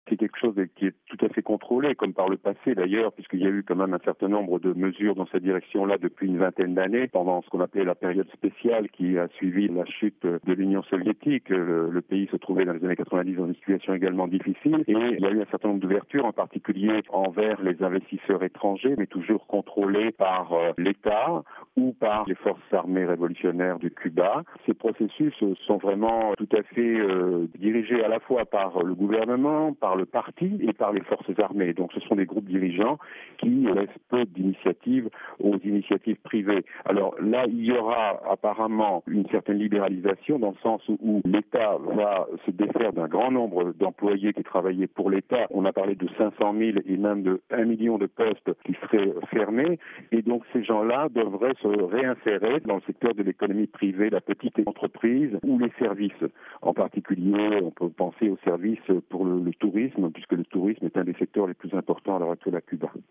Propos recueillis par